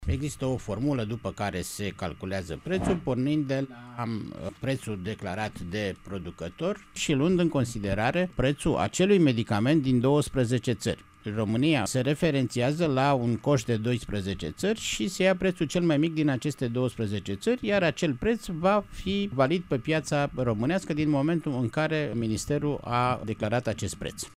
Preşedintele Casei Naţionale de Asigurări de Sănătate, Vasile Ciurchea a explicat pentru Radio România Actualităţi.